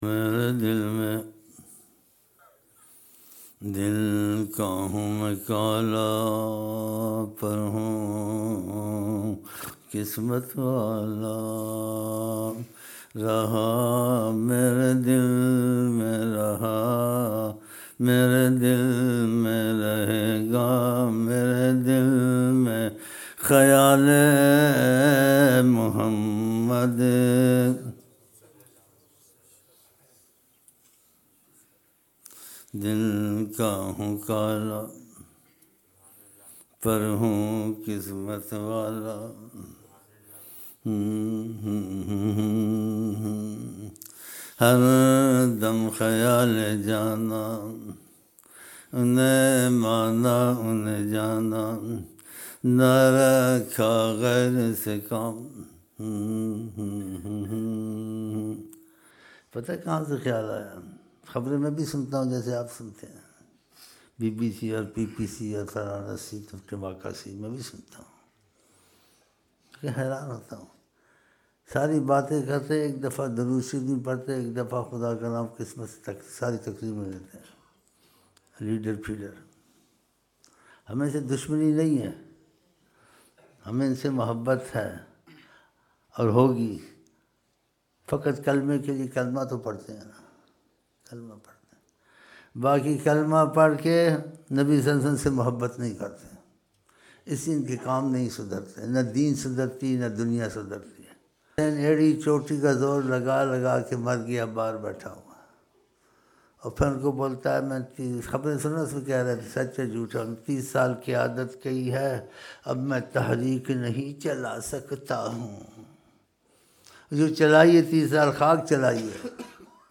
06 January 2007 Saturday Fajar Mehfil (15 Dhul Hijjah 1427 AH)